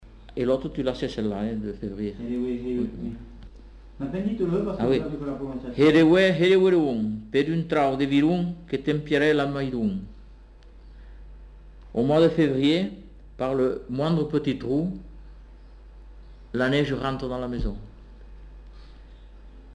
Lieu : Bagnères-de-Luchon
Genre : forme brève
Effectif : 1
Type de voix : voix d'homme
Production du son : récité
Classification : proverbe-dicton